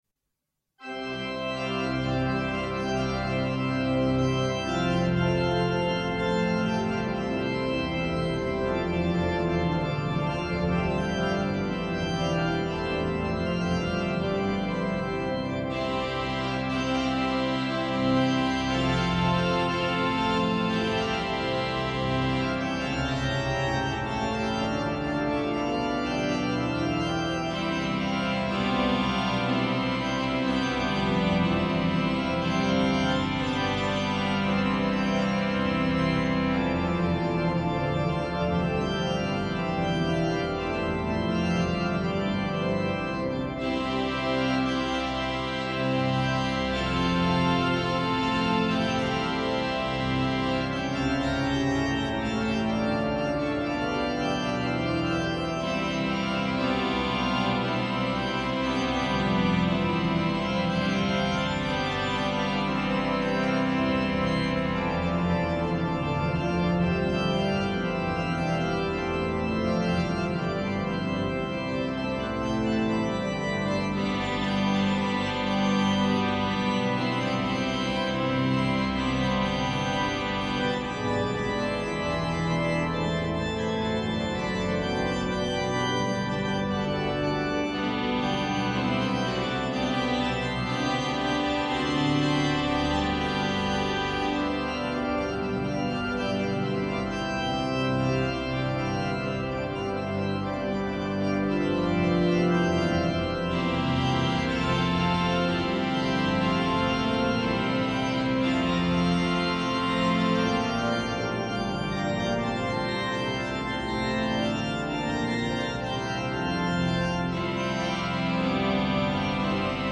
Concierto de Otoño a la Luz de las Velas
on the new Allen Quantum organ at the Cathedral of Valladolidin in Northern Spain.
all live recorded and published on this page.
Transcription for organ by the most famous American concert organist Virgil Fox (1912 - 1980).